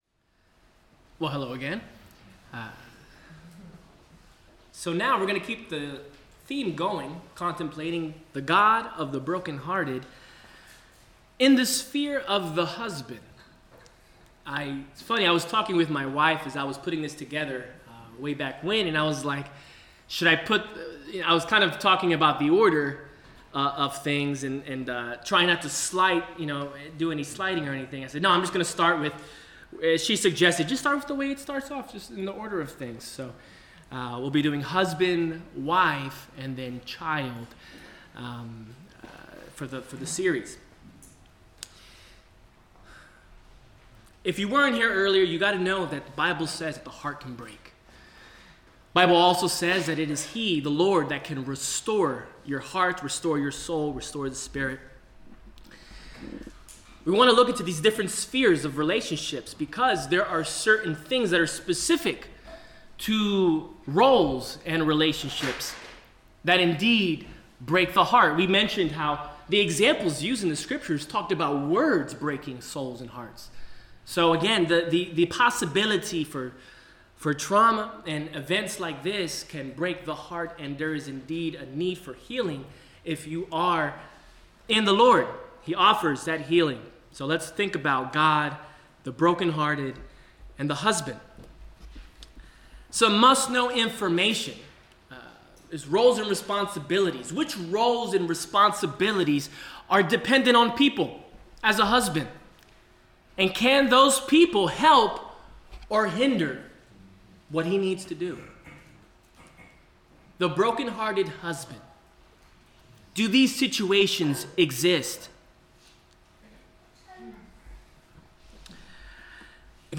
Series: God of the Brokenhearted Service Type: Sermon